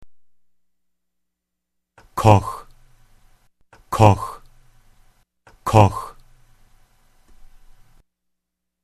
koch koch koch it's more like kawch brothers